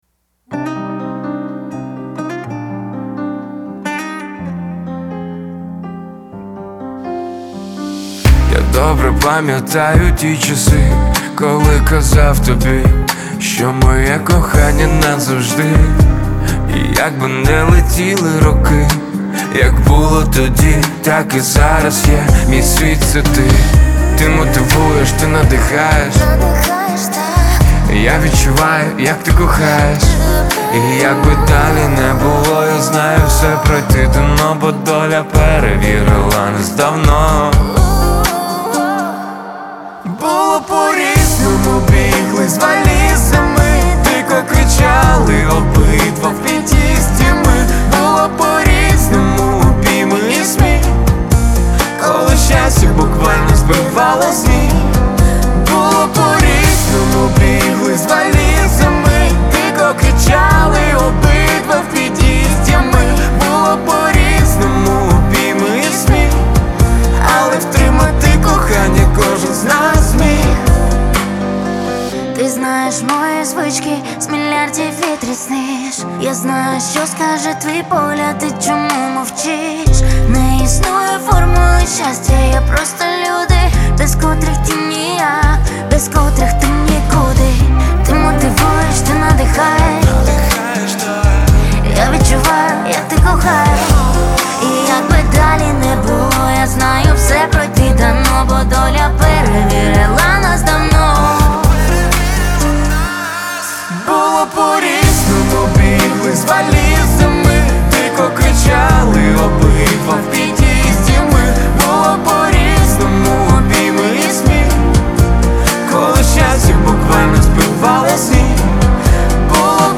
• Жанр:Поп